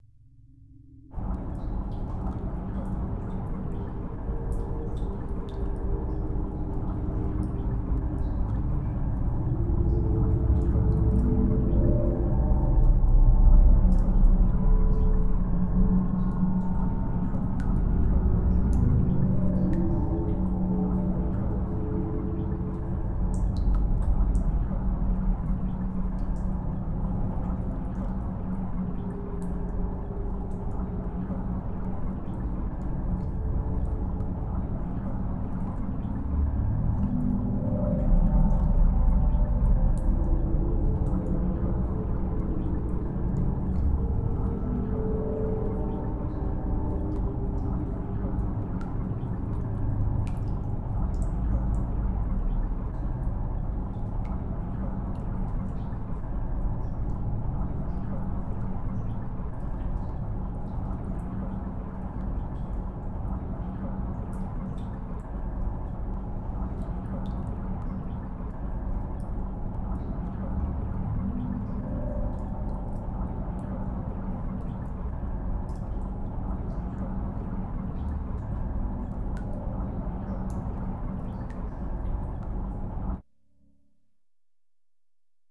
cave_ambience_ambisonic.wav